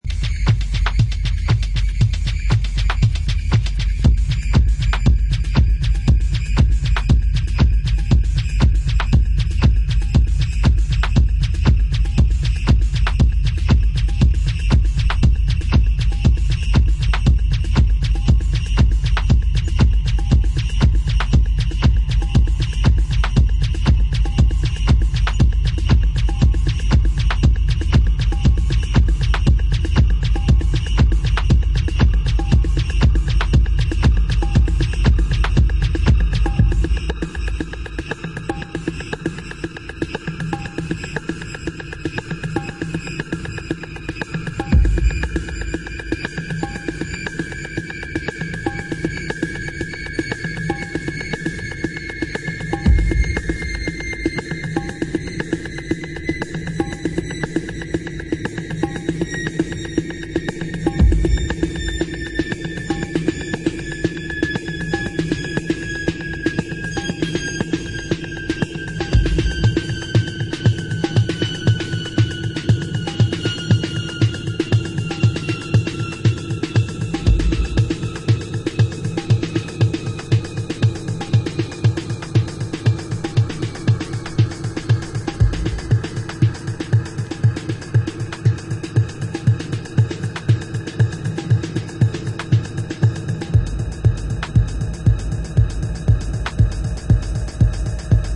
New Techno label